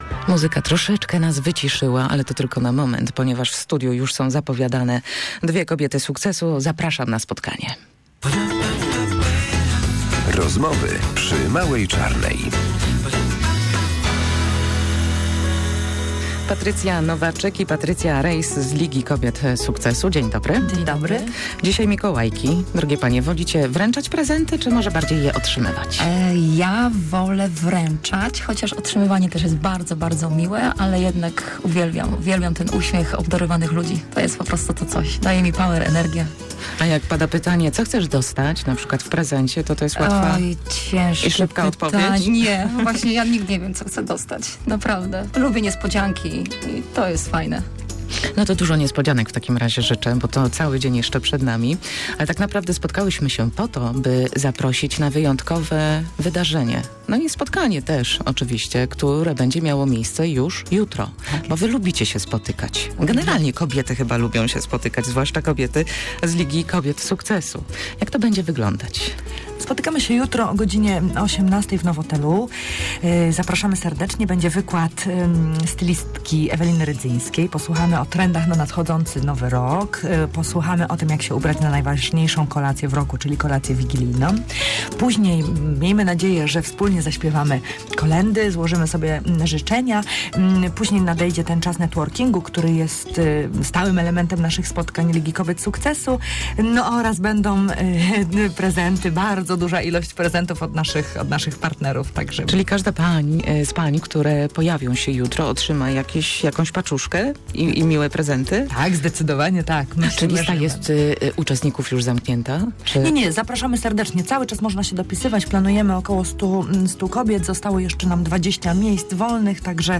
W ten sposób piszą o sobie przedstawicielki Ligi Kobiet Sukcesu, które dziś gościliśmy w naszym studiu.